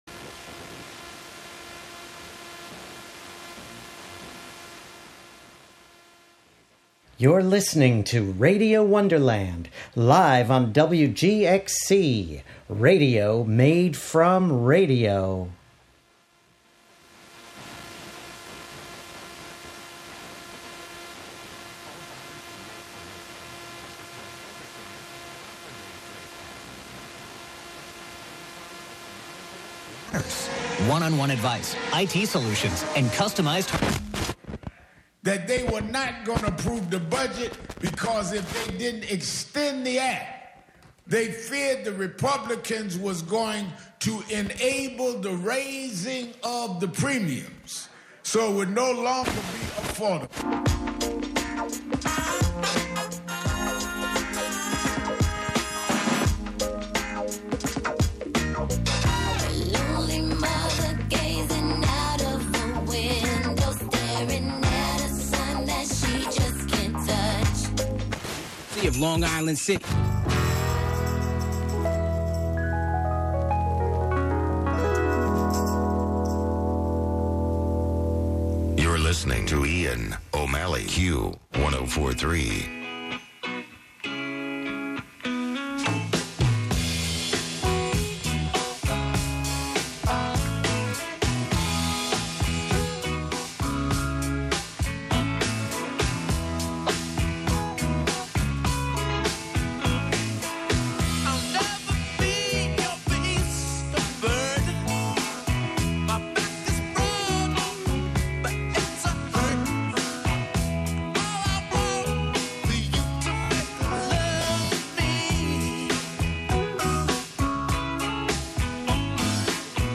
11am Live from Brooklyn, New York